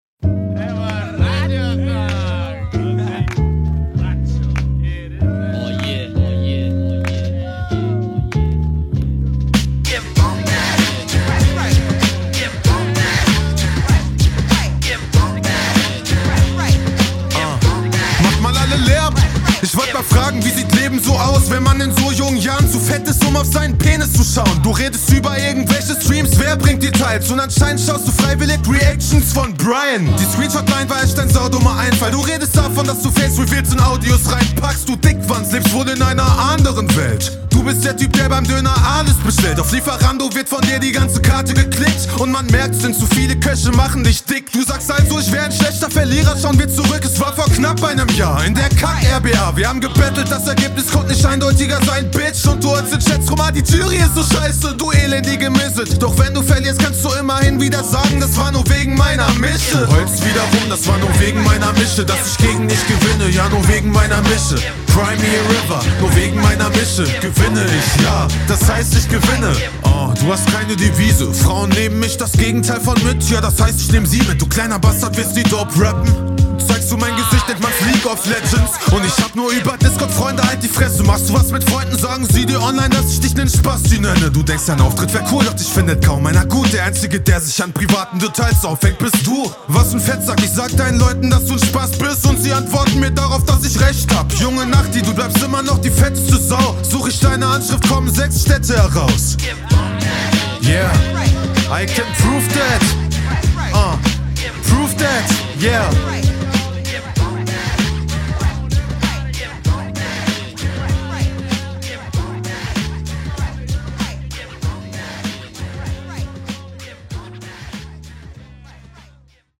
die laute Delivery gefällt mir auf dem Beat nicht so wie der chillige Vibe bei …